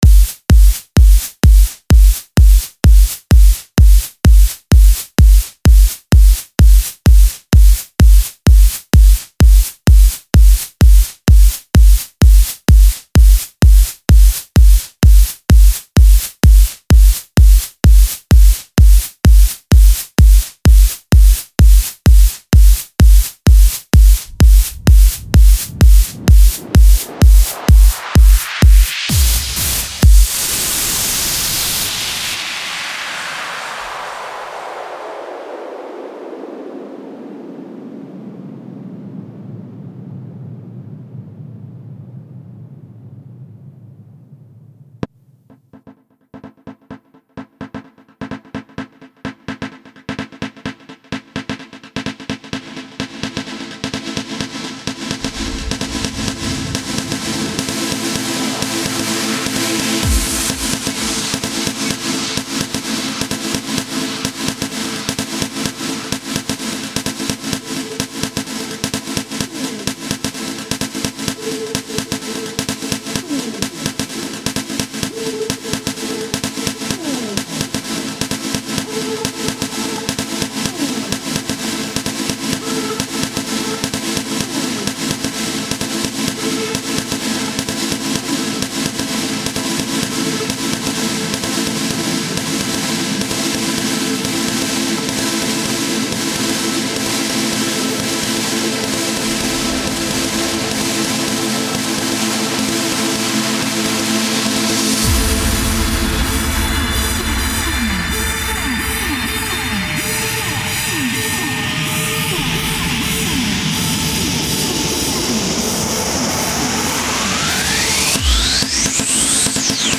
Жанр:Electro